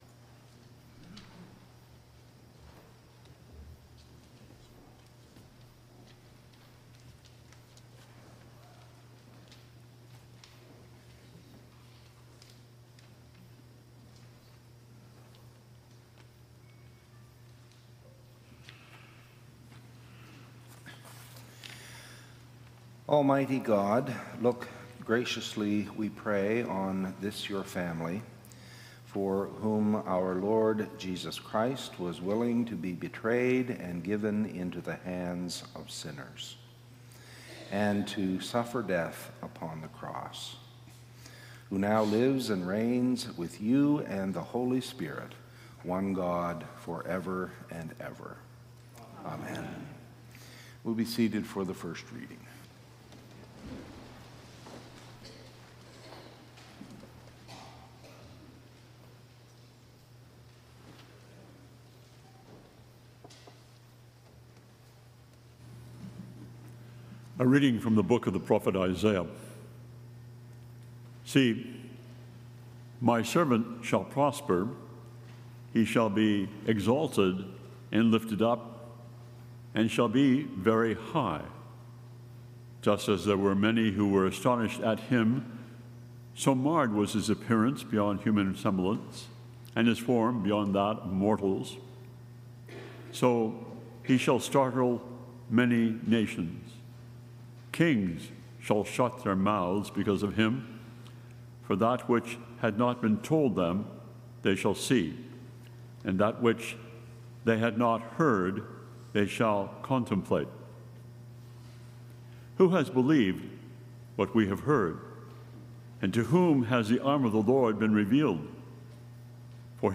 Podcast from Christ Church Cathedral Fredericton
WORSHIP - 10:00 a.m. Good Friday